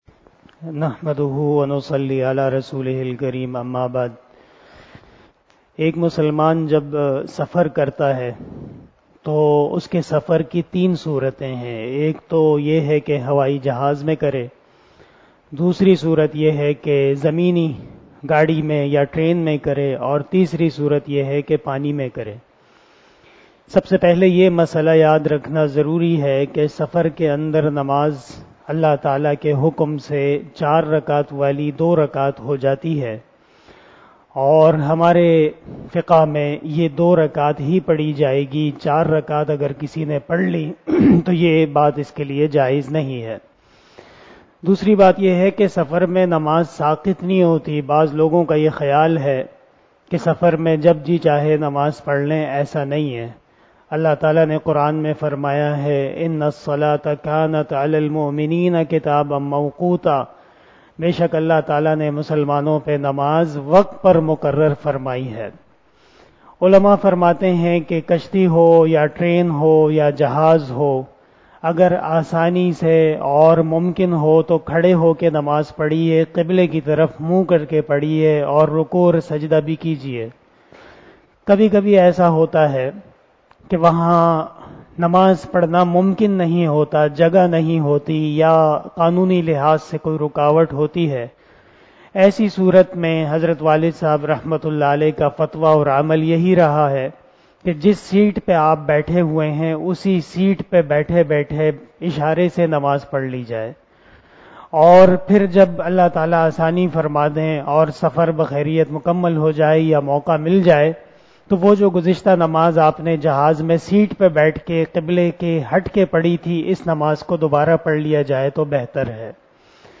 058 After Traveeh Namaz Bayan 25 April 2022 ( 24 Ramadan 1443HJ) Monday